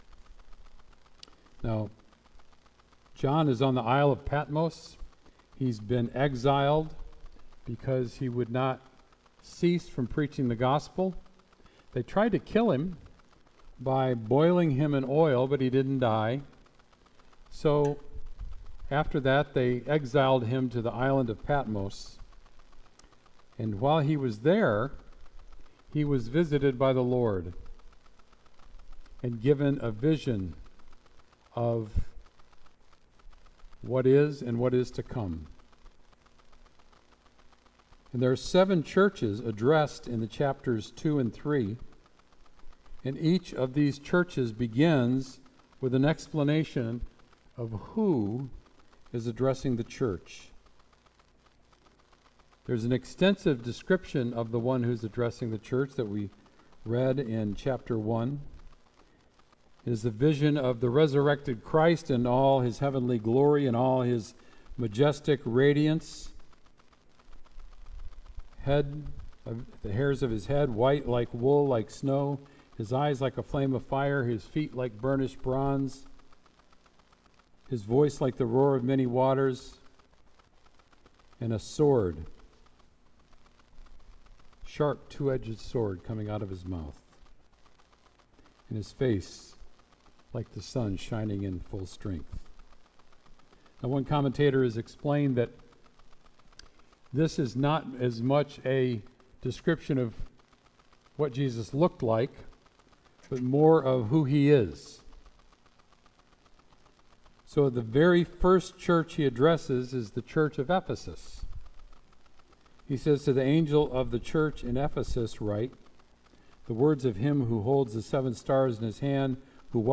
Sermon “Stars and Lampstands”